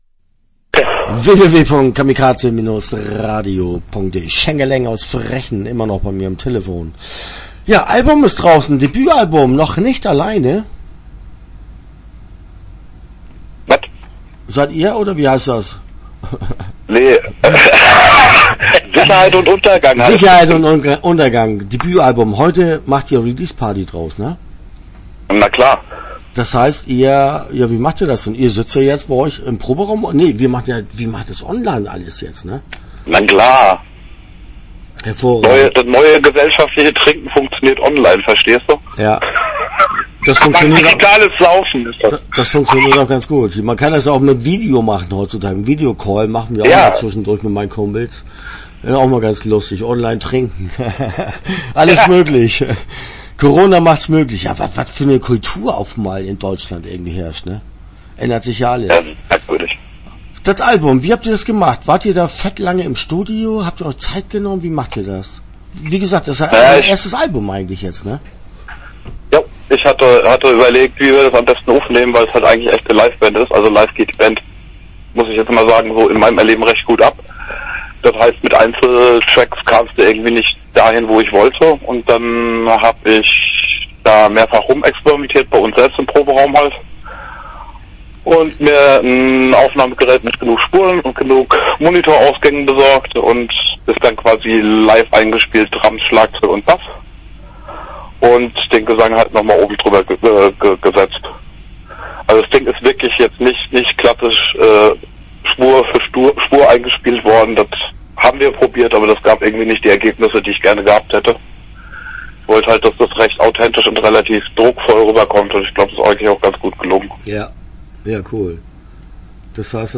Schängeläng - Interview Teil 1 (10:40)